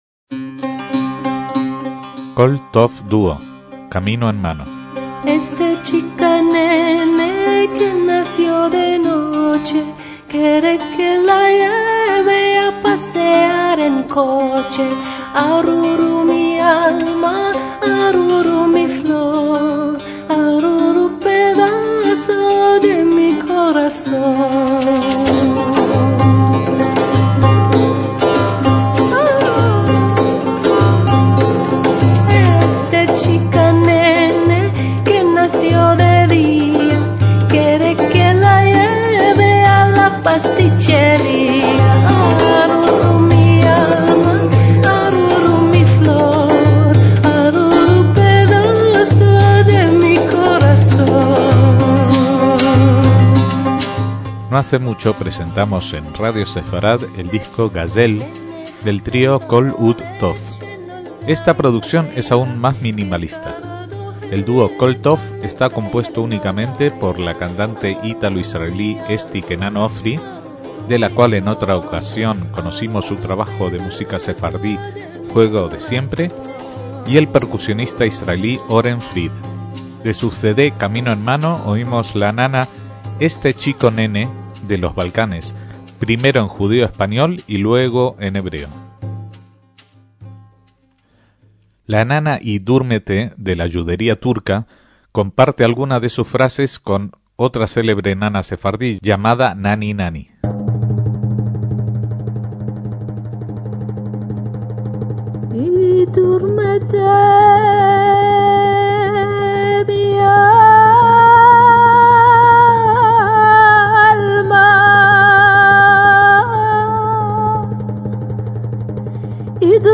PONLE NOTAS - En este álbum apenas dos personas son capaces de servir la esencia del canto sefardí, bien a través de canciones tradicionales, bien a través de adaptaciones de textos de grandes autores judeoespañoles (como Samuel Haguid Ibn Nagrella) a melodías de otras tradiciones.
algunas percusiones sencillas
cordófono turco saz